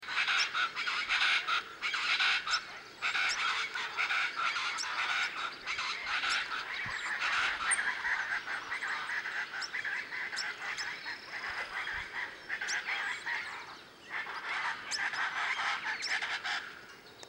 Chaco Chachalaca (Ortalis canicollis)
Sex: Both
Life Stage: Adult
Detailed location: Rio Marapa
Condition: Wild
Certainty: Observed, Recorded vocal